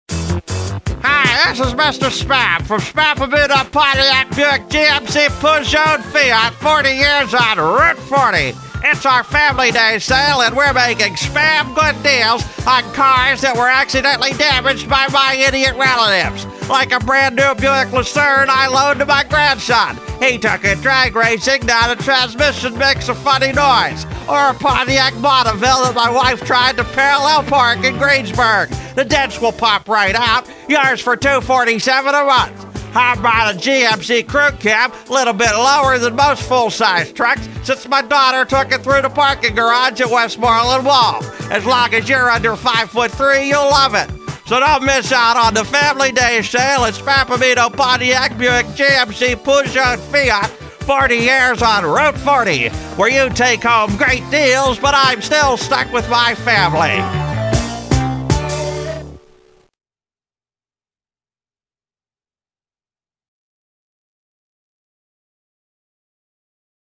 Rather than post a picture of canned goulash again, here are two recent spots from my alleged radio show.